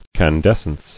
[can·des·cence]